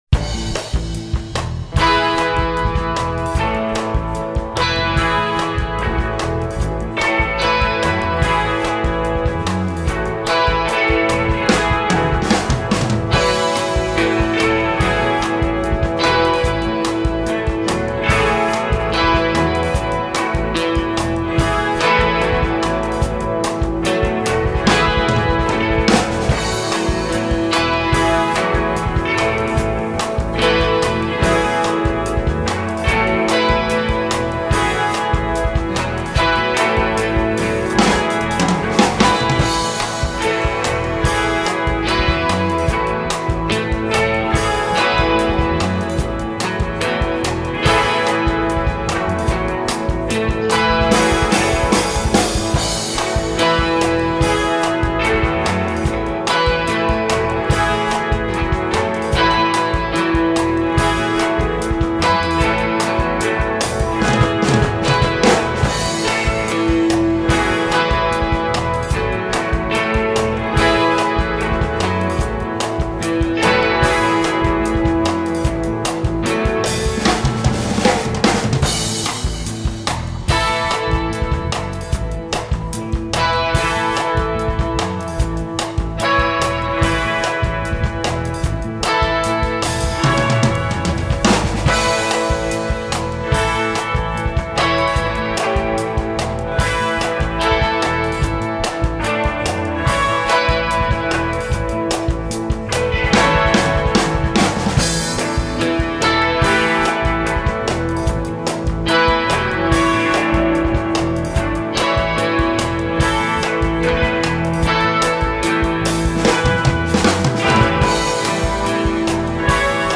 for 100 electric guitars, electric bass and drums